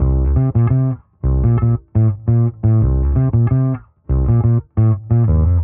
Index of /musicradar/dusty-funk-samples/Bass/85bpm
DF_PegBass_85-C.wav